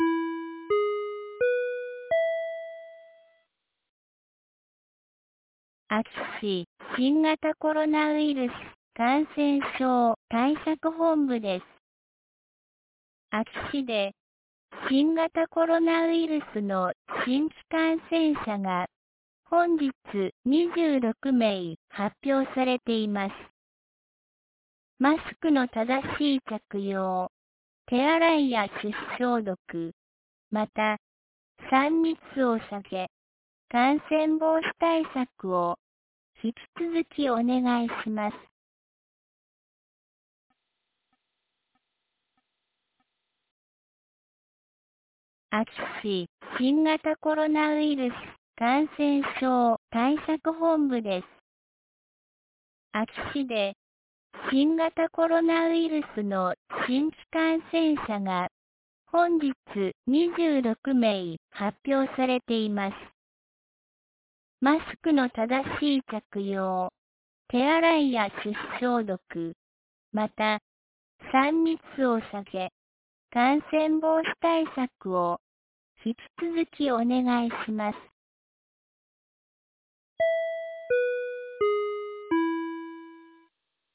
2022年08月07日 17時06分に、安芸市より全地区へ放送がありました。